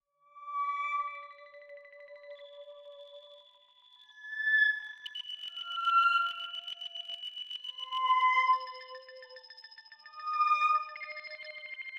混音 " 令人毛骨悚然的鼓
描述：使用Ableton制作万圣节曲目的是鼓样
Tag: 怪异 万圣节 节拍 creeby 吓人